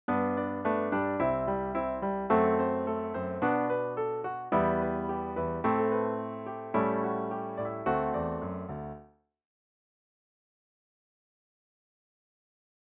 1. Create a circle of fifths progression where the end of the progression connects nicely back to the beginning. (Ex: G  C  F#dim  Bm  Em  Am  A  D…)
For the next phrase, start on the F#dim, and start the melodic phrase on an A. Sing the notes A-C-A-C, ending on a D while you strum a Bm. (You’ll notice that all you’ve done is repeat everything a scale-degree lower.)
You’ve now got a melody that’s four short phrases long.